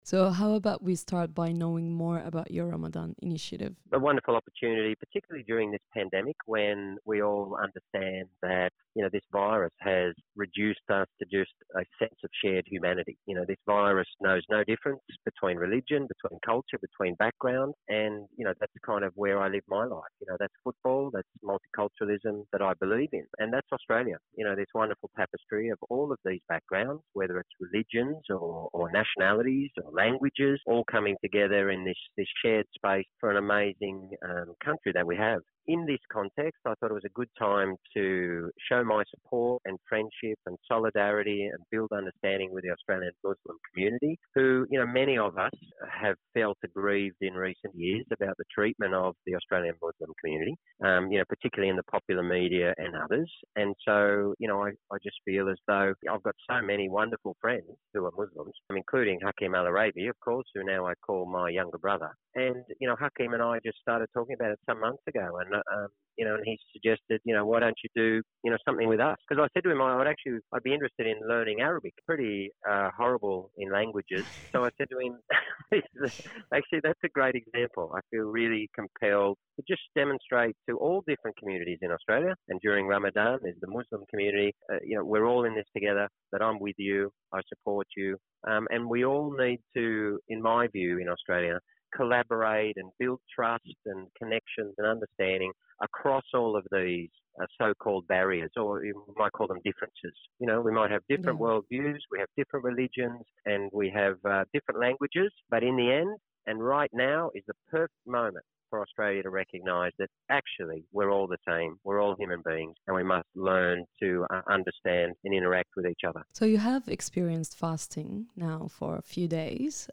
The former Socceroo and current football analyst tells SBS Arabic24 that the holy month presented a good time to show his support and solidarity with Australia’s Muslims, who he says have been “mistreated” in the community over recent years.